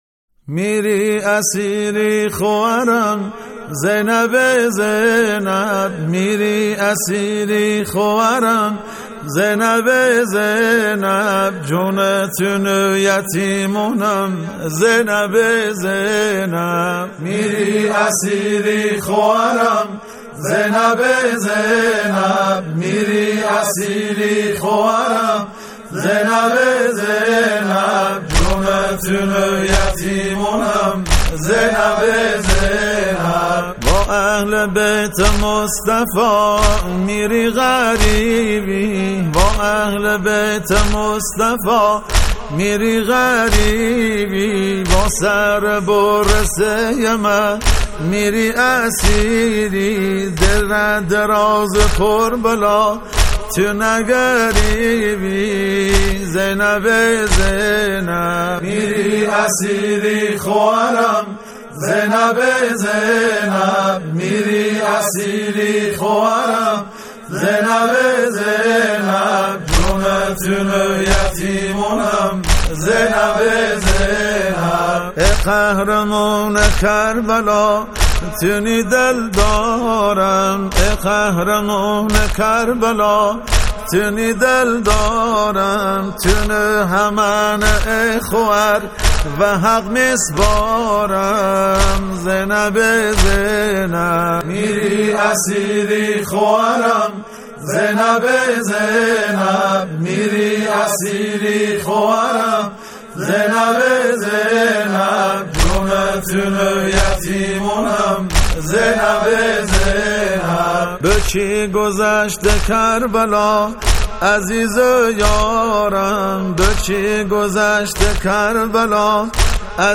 نوحه لری